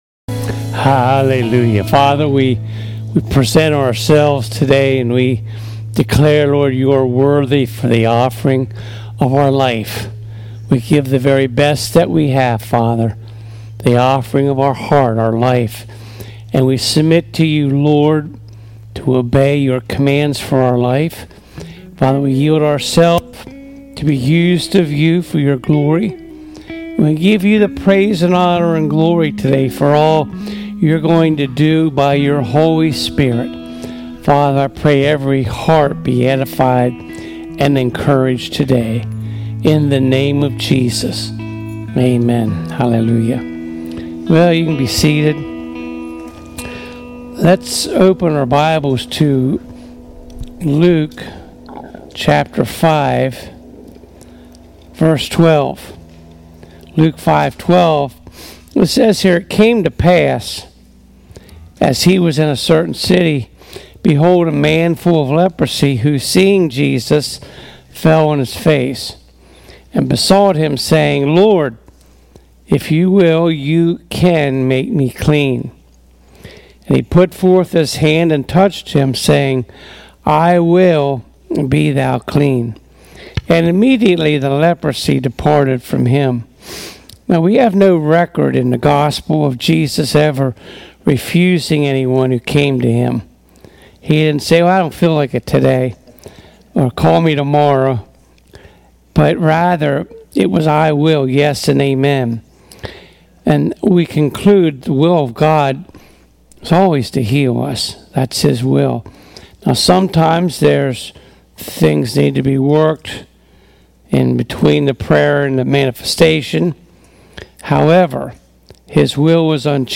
Preaching Service